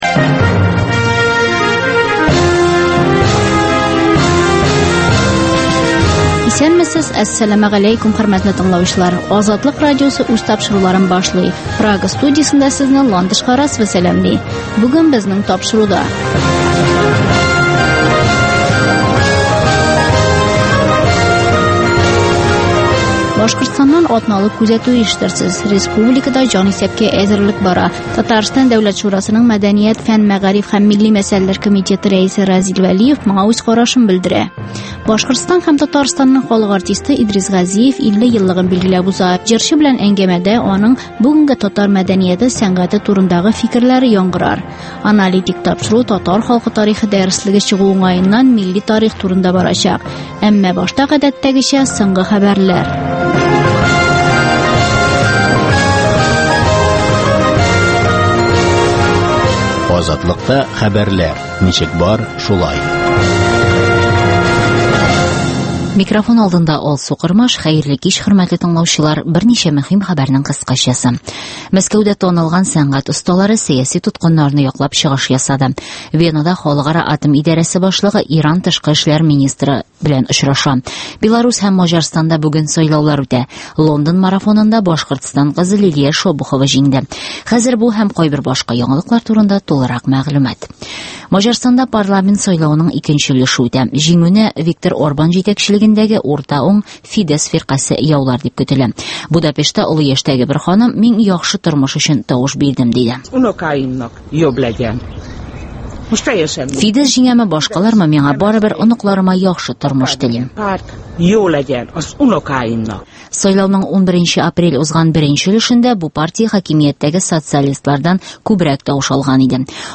Азатлык узган атнага күз сала - соңгы хәбәрләр - башкортстаннан атналык күзәтү - татар дөньясы - түгәрәк өстәл сөйләшүе